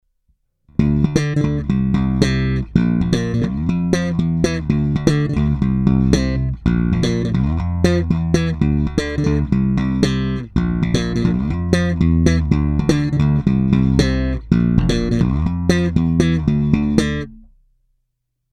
Ukázky jsou nahrány rovnou do zvukové karty a jen normalizovány.
Slap na oba snímače